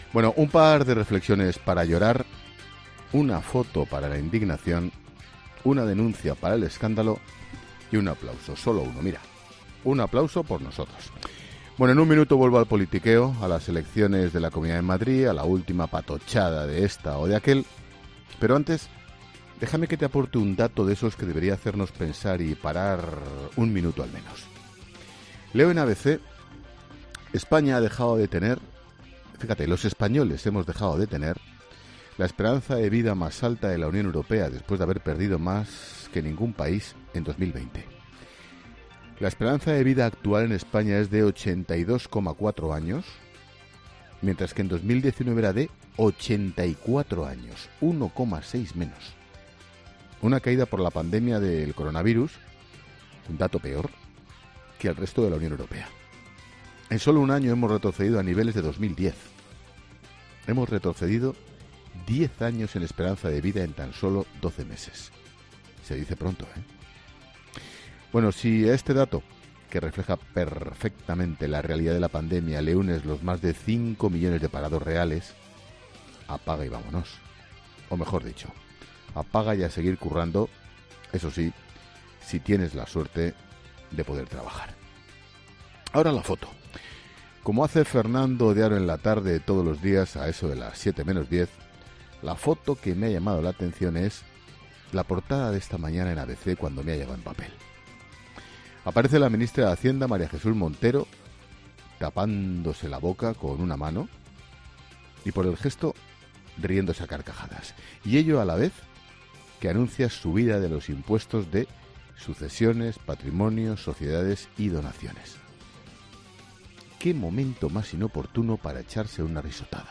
Monólogo de Expósito
El director de 'La Linterna', Ángel Expósito, reflexiona en su monólogo sobre las principales cuestiones informativas que deja este martes 13 de abril